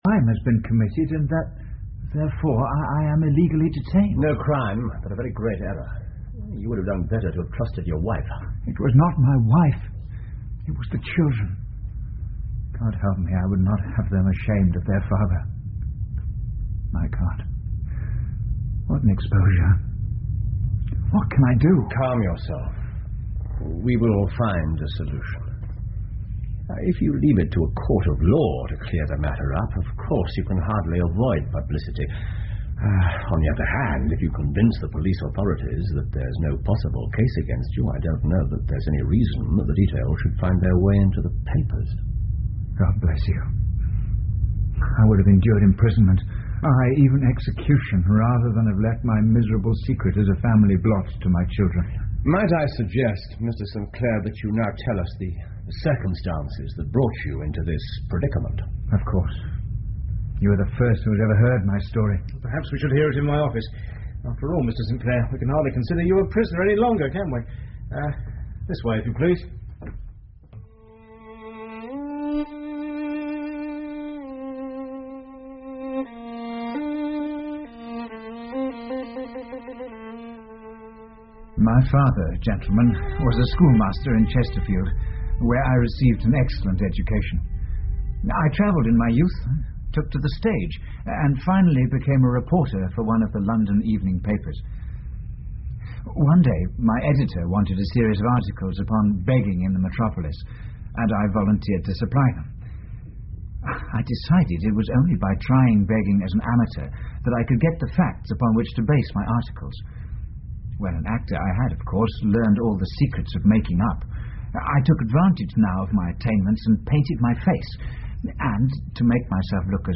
福尔摩斯广播剧 The Man With The Twisted Lip 8 听力文件下载—在线英语听力室